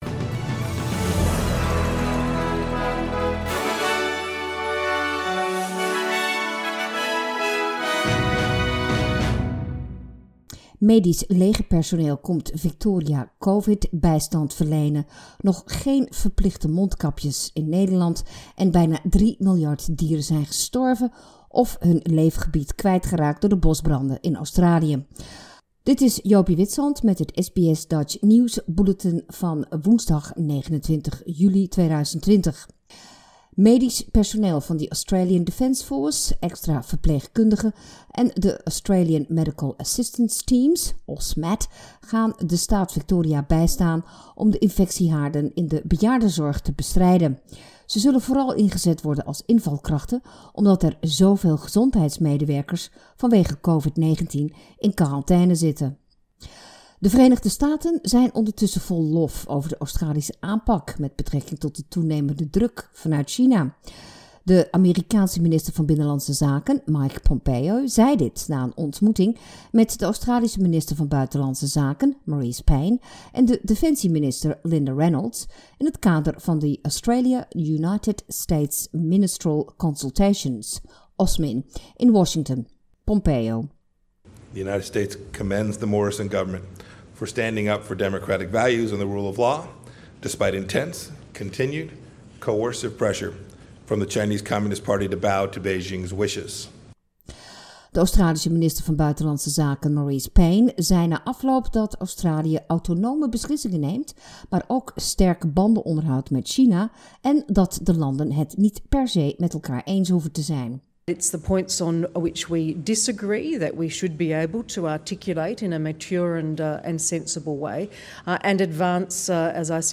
Nederlands/Australisch SBS Dutch nieuws bulletin woensdag 29 juli